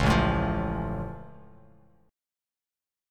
Listen to A+ strummed